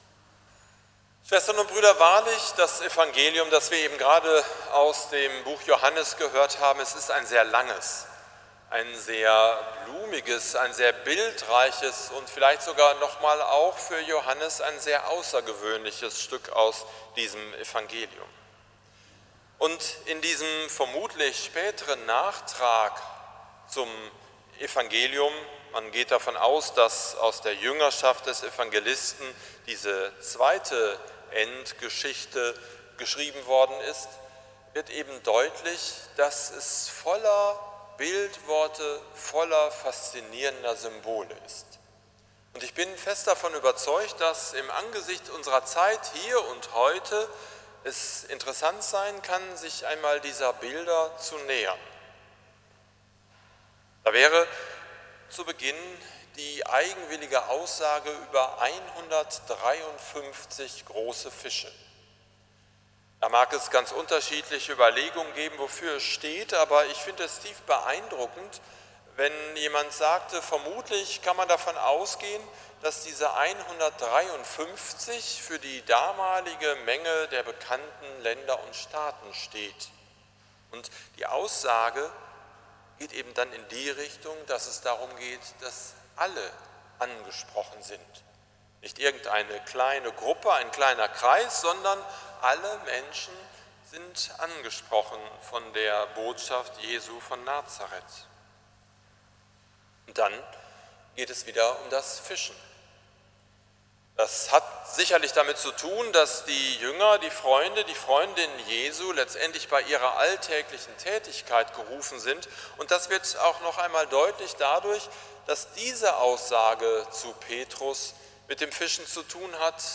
Predigt zum 3. Sonntag der Osterzeit 2019 – St. Nikolaus Münster
predigt-zum-3-sonntag-der-osterzeit-2019